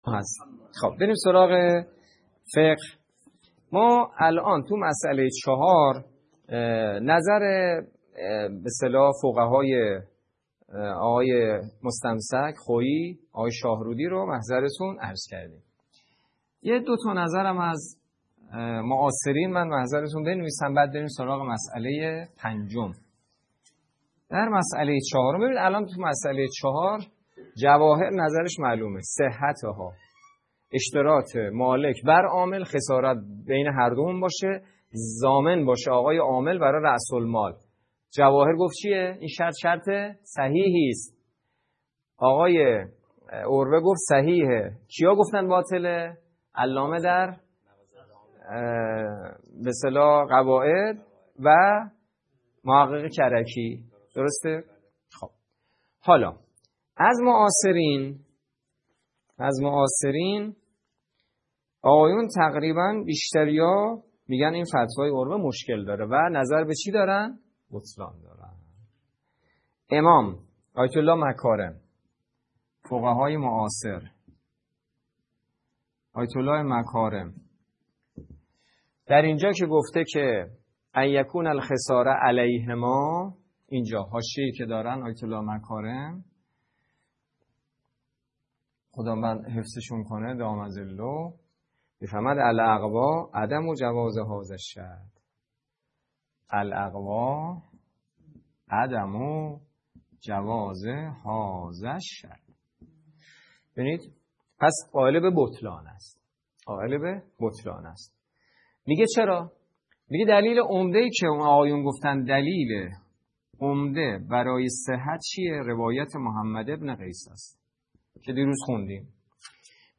پایگاه اطلاع‌رسانی دفتر حضرت حجت الاسلام والمسلمین سید سعید حسینی؛ نماینده ولی فقیه و امام جمعه کاشان
درس فقه